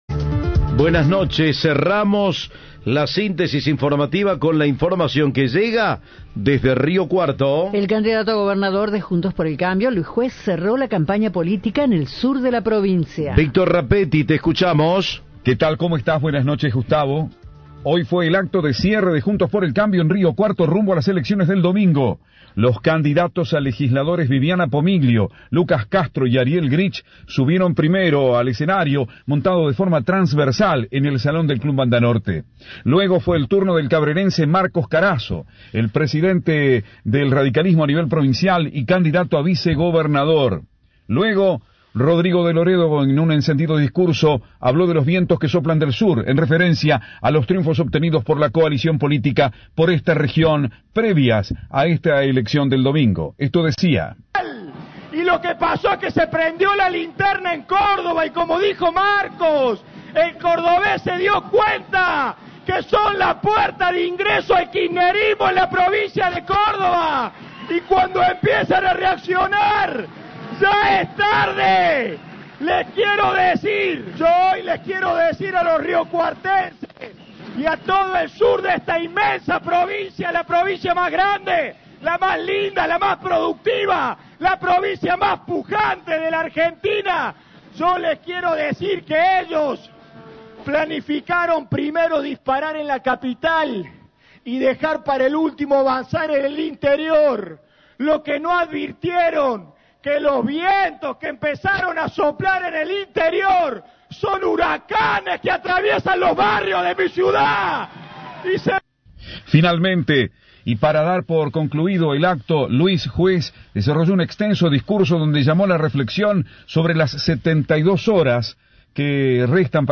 Audio. Luis Juez cerró su campaña en el interior provincial con un acto en Río Cuarto